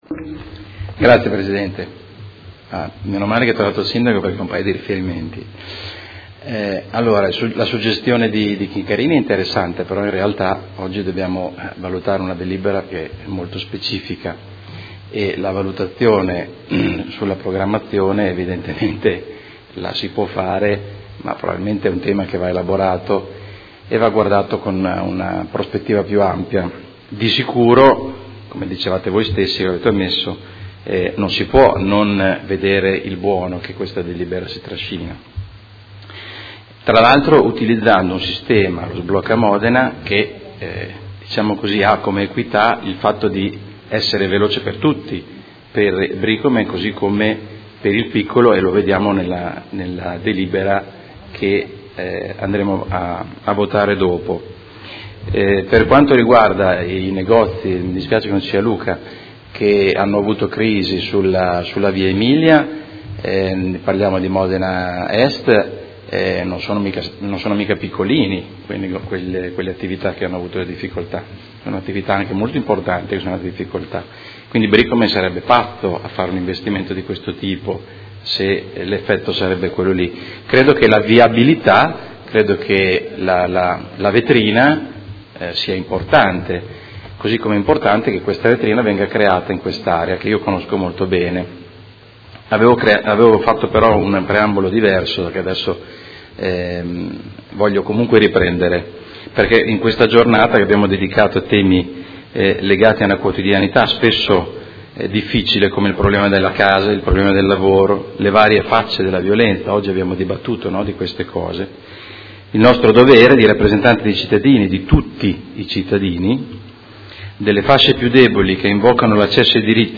Carmelo De Lillo — Sito Audio Consiglio Comunale
Seduta del 19/05/2016.
Dibattito